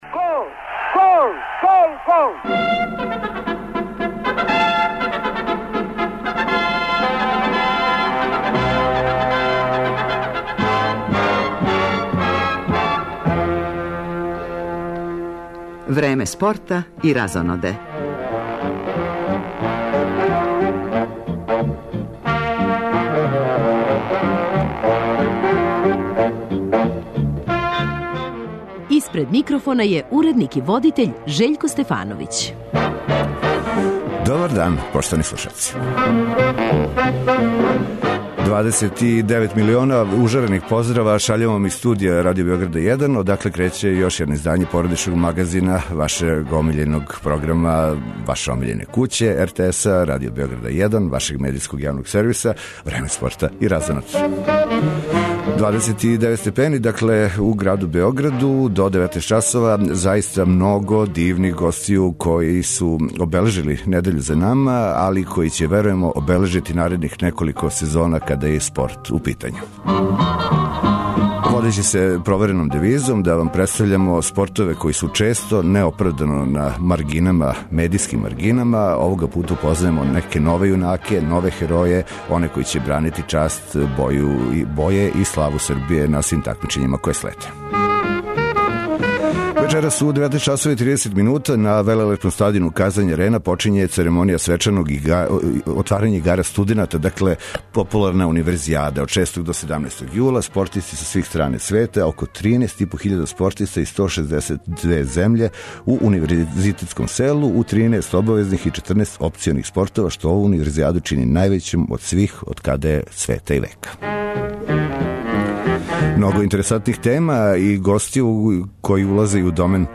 Много гостију и ове суботе у породичном магазину Радио Београда 1. Емисију почињемо актуелностима с тениског турнира у Вимблдону и одјецима жреба за Лигу шампиона у кошарци,а потом се нижу млади и успешни људи, свако понаособ у послу којим се бави.